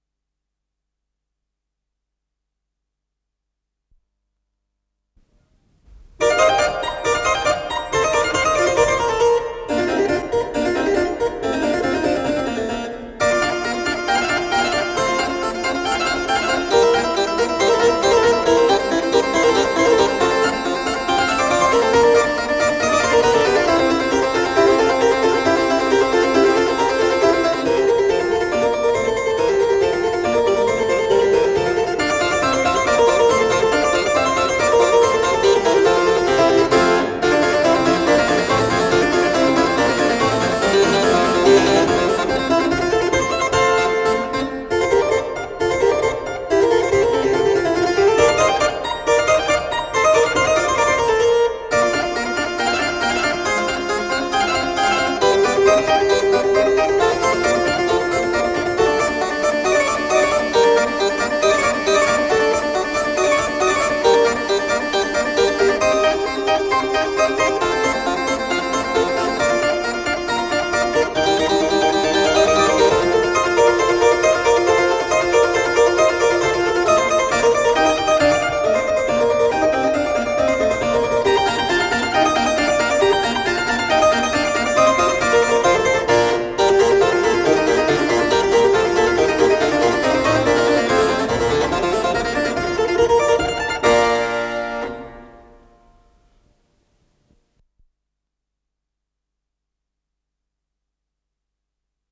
ДКС-45251 — Соната для клавесина — Ретро-архив Аудио
с профессиональной магнитной ленты
клавесин
ВариантДубль моно